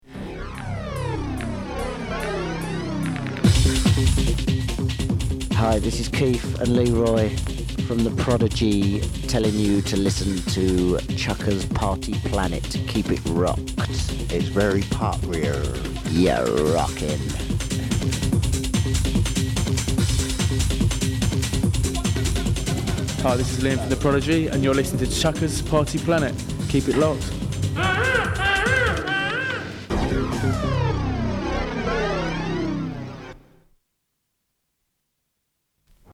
The Prodigyn ID radio-ohjelmaan.
25-The-Prodigy-ID-3-musiikilla.mp3